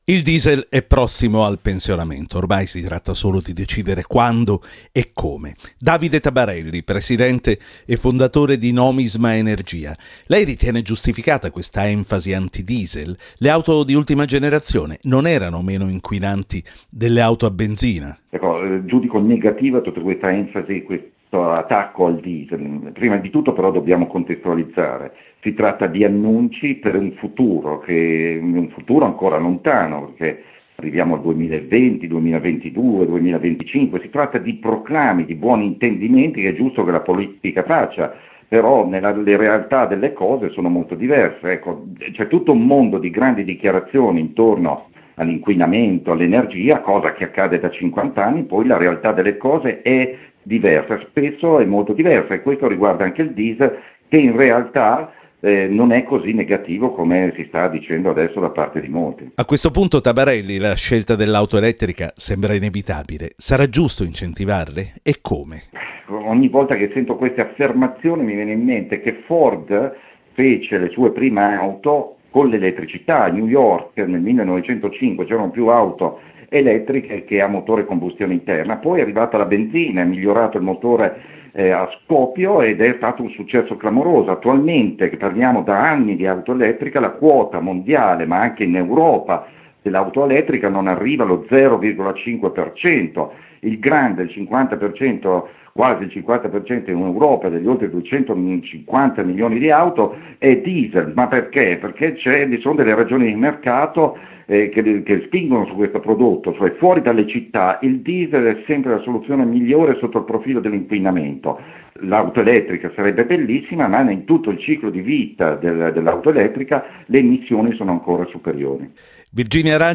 intervista
conversazione